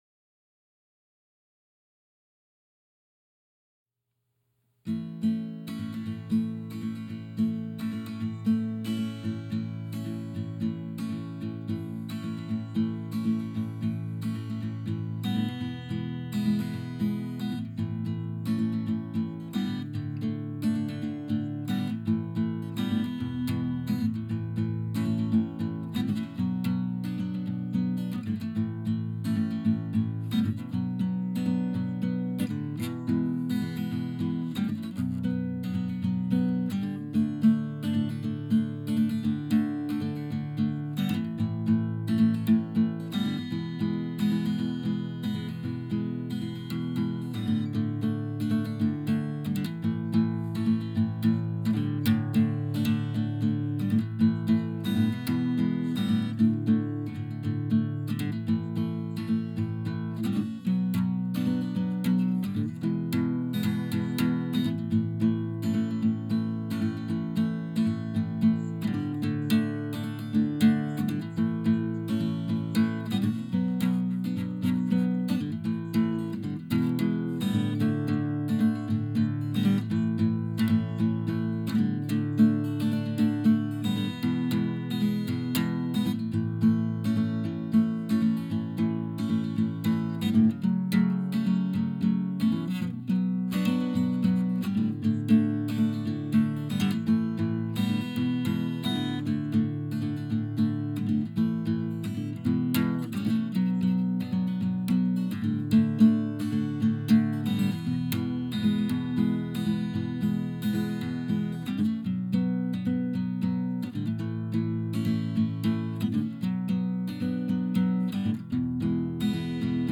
guitars.wav